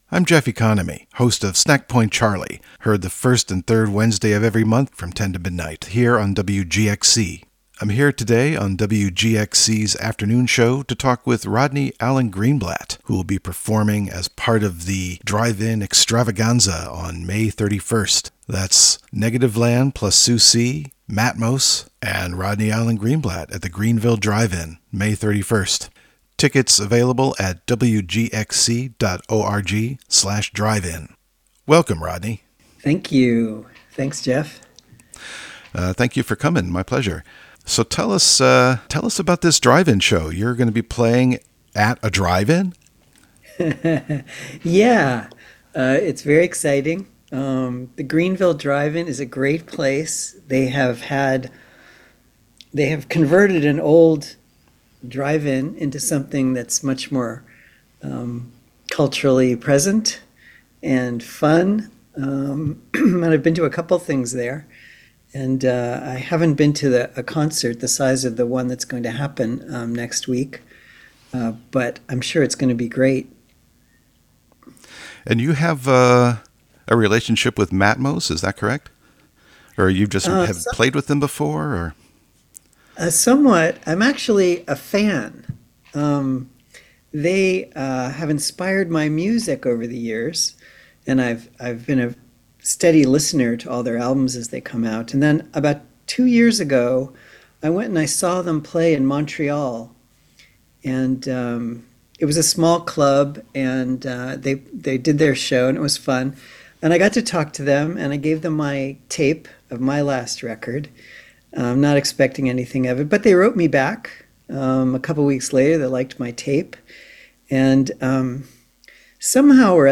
Interview with Rodney Alan Greenblat (Audio)